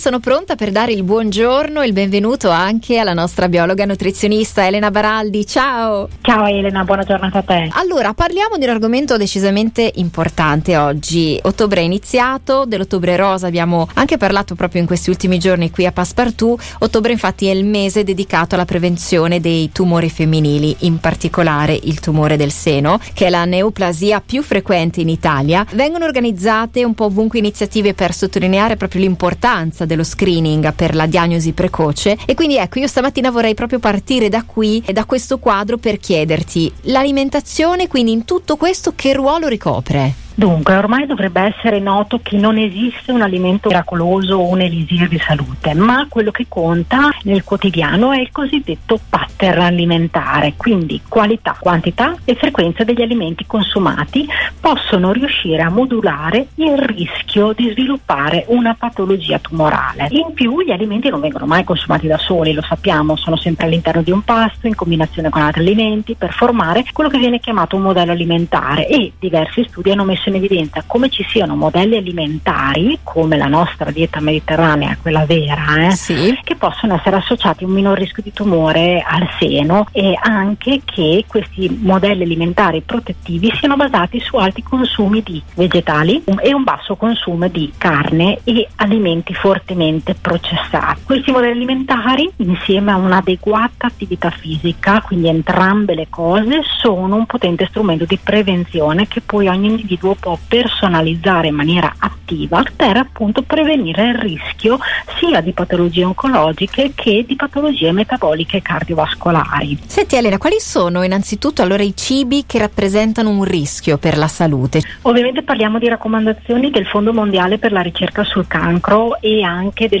biologa nutrizionista